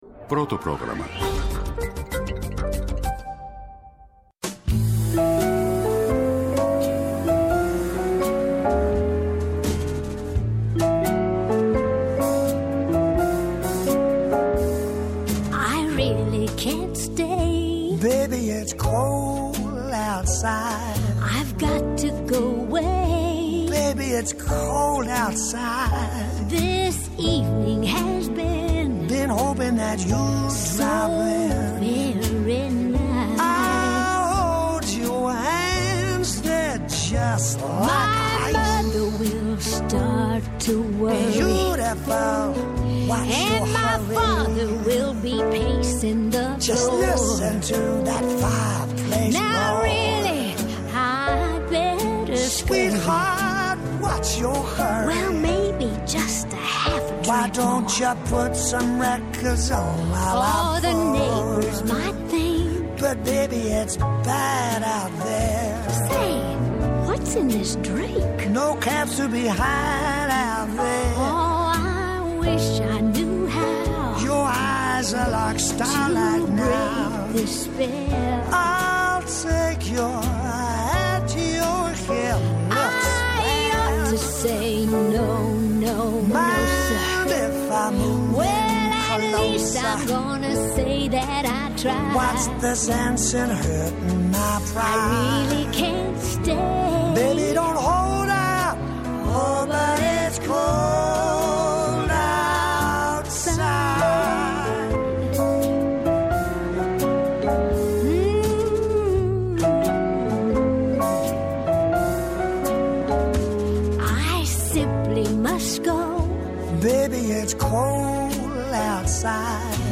Καλεσμένος σήμερα στην εκπομπή “Ναι μεν Αλλά” είναι ο Δημήτρης Τζανακόπουλος, βουλευτής της Νέας Αριστεράς.
Από Δευτέρα έως Πέμπτη 11 με 12 το μεσημέρι στο Πρώτο Πρόγραμμα.